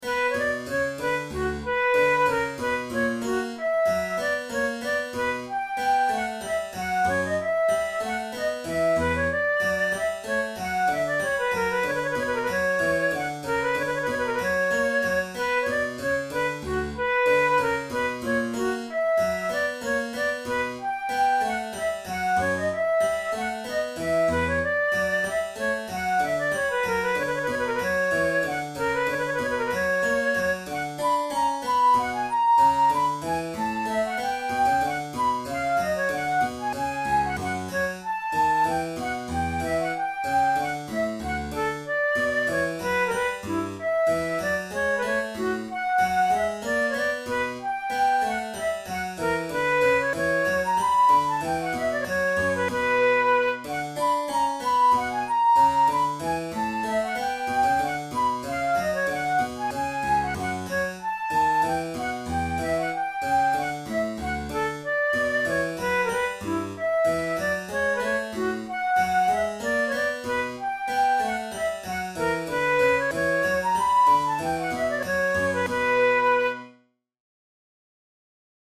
from Flute Sonata in B minor
This is the seventh and last movement of a sonata in B minor for flute and harpsichord, sometimes referred to as HWV 367b, by George Frideric Handel.
Categories: Baroque Sonatas Written for Flute Difficulty: intermediate
handel-flute-sonata-9b-in-b-minor-vii-a-tempo-di-minuet.mp3